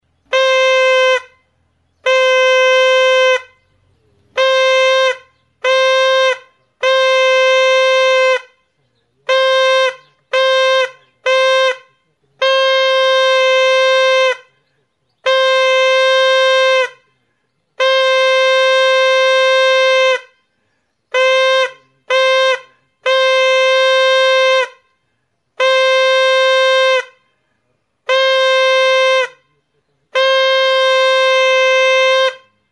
Aerófonos -> Lengüetas -> Simple (clarinete)
EHIZARAKO KORNETA. Oiartzun, 26/02/2021.
Grabado con este instrumento.
Ahokoak metalezko mihi bakuneko fita du.